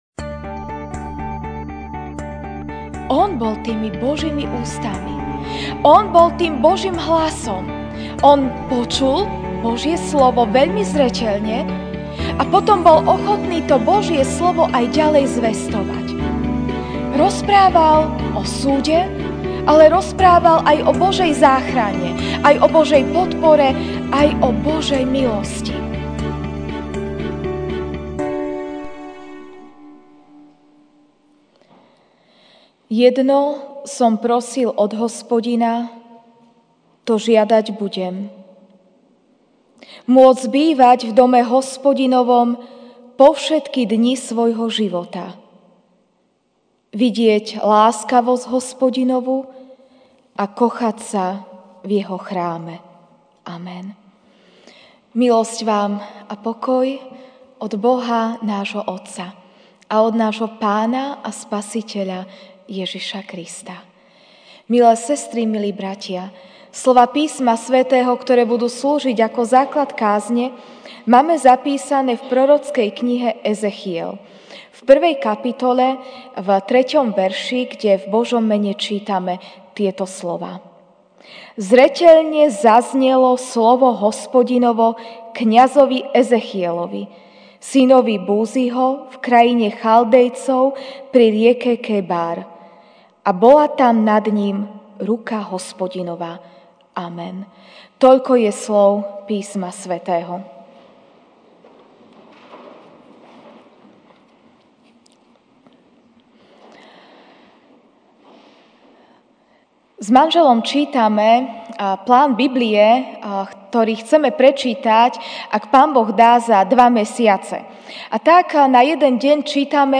feb 24, 2019 Zaznelo Slovo Hospodinovo MP3 SUBSCRIBE on iTunes(Podcast) Notes Sermons in this Series Večerná kázeň: Zaznelo Slovo Hospodinovo (Ez 1, 3) zreteľne zaznelo slovo Hospodinovo kňazovi Ezechielovi, synovi Búziho, v krajine Chaldejcov pri rieke Kebár.